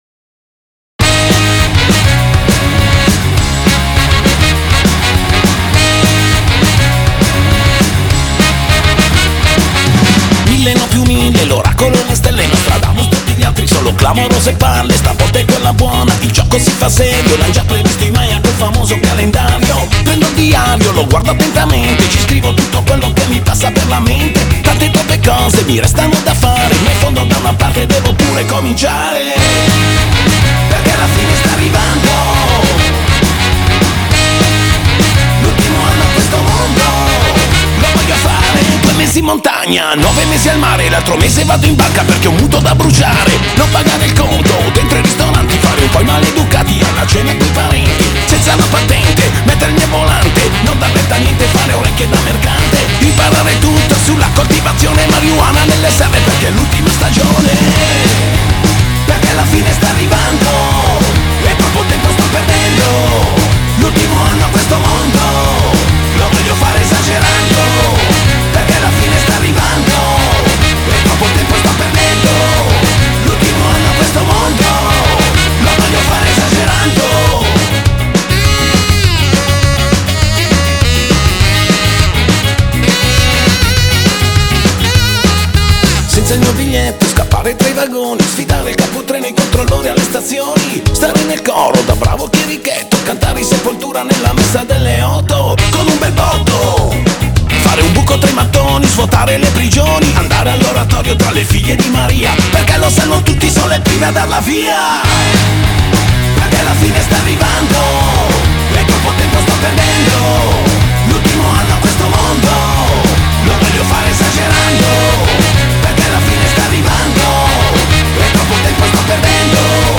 Genre: Rock, Alternative, Punk-Ska